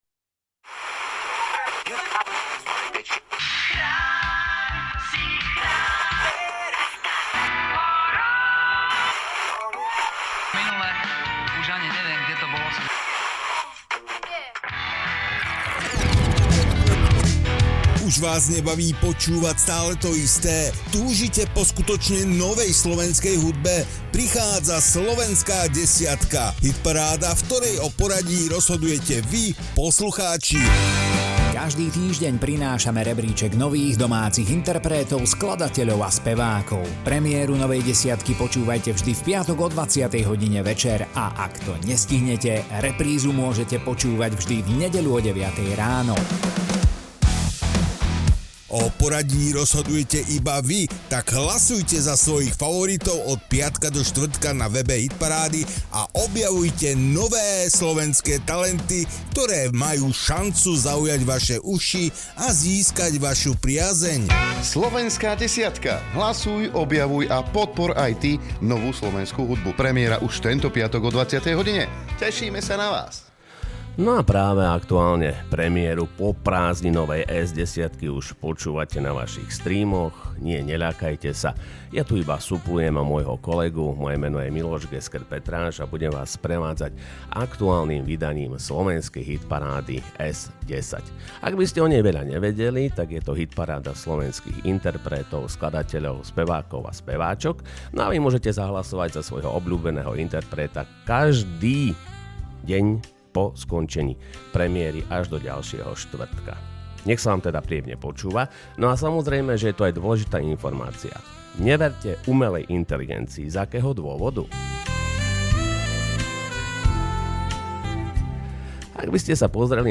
Hitparáda, ktorá dáva priestor slovenskej hudbe!